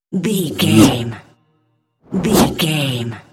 Whoosh fast creature
Sound Effects
Fast
whoosh